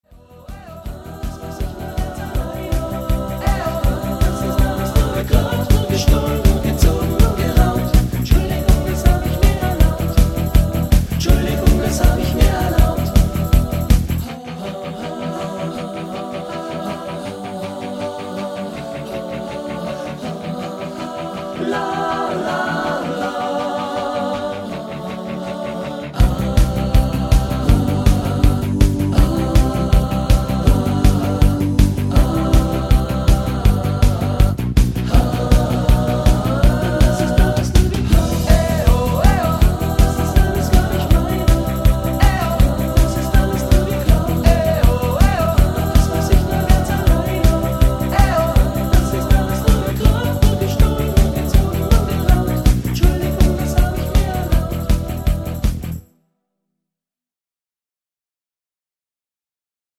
Rhythmus  Rock
Art  Pop, Deutsch